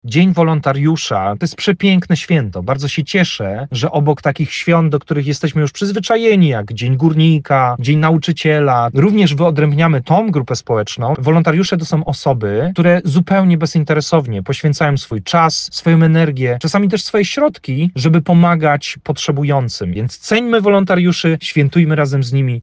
trener mentalny.